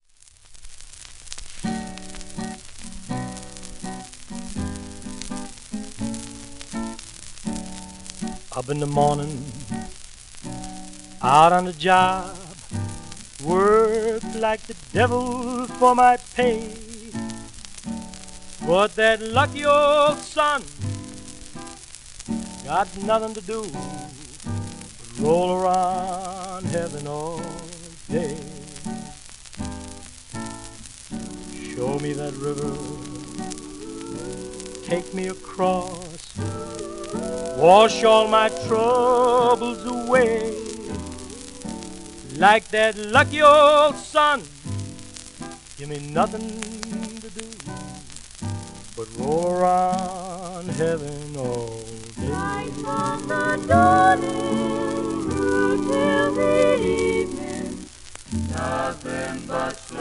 w/オーケストラ
盤質:A-/B+ *面擦れ、盤反り、キズ